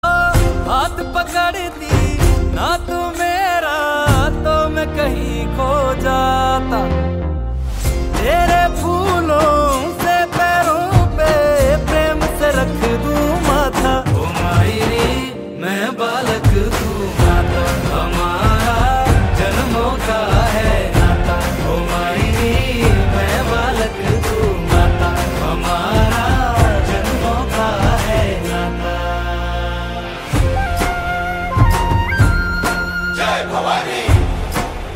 Devotional Ringtones